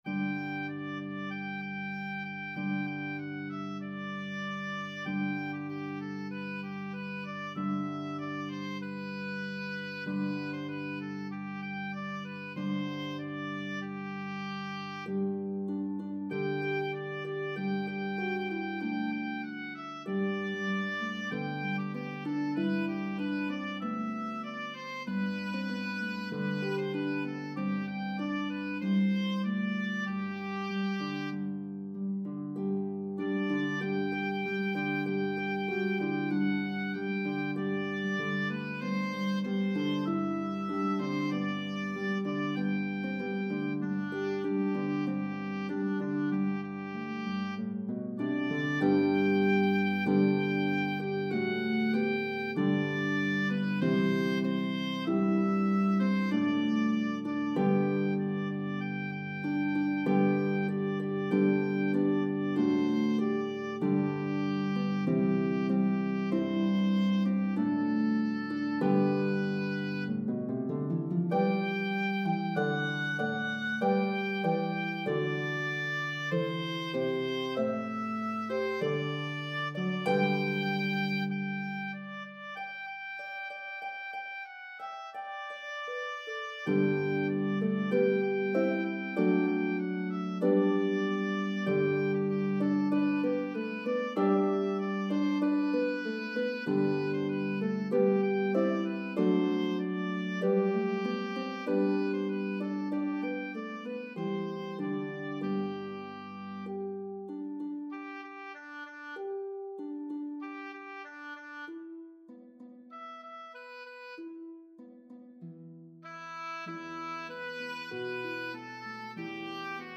a medley of traditional English & Irish Carols